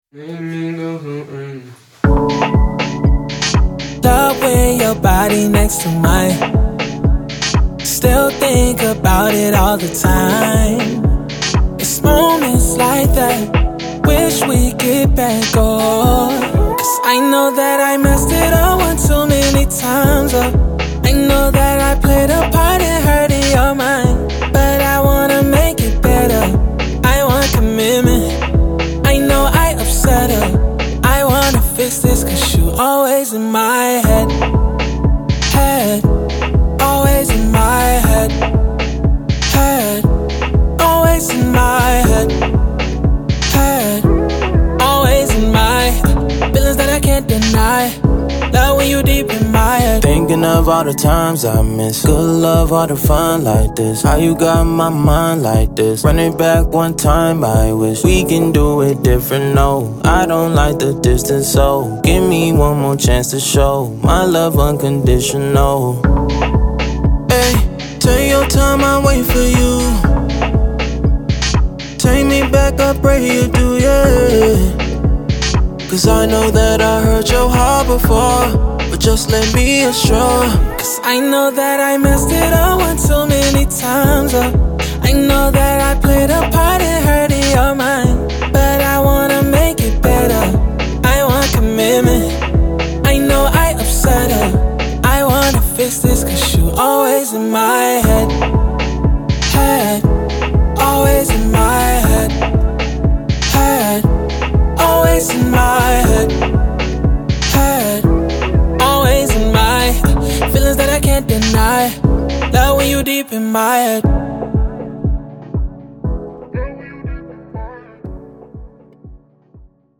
Pop, Afrobeat
F# Minor